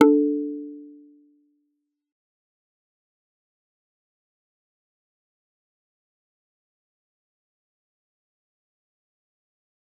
G_Kalimba-D4-f.wav